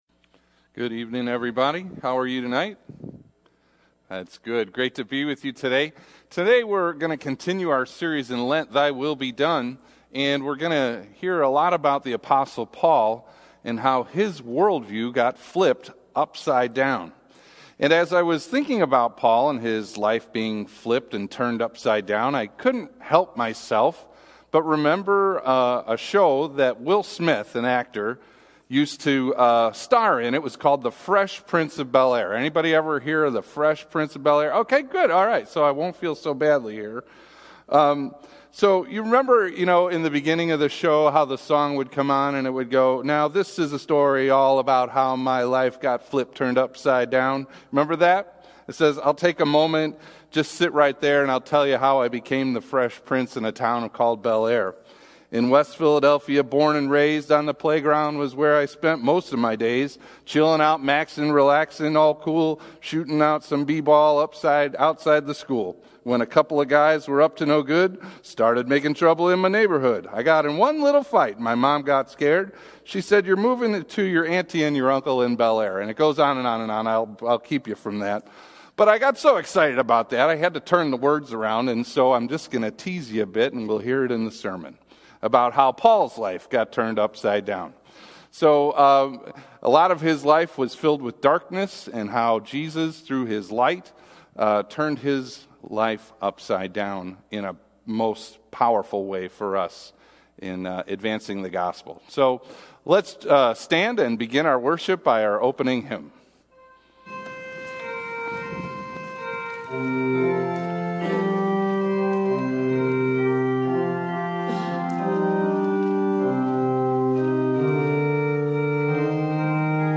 Mar 10 / Sat Blended – The Fresh Prince of Damascus – Lutheran Worship audio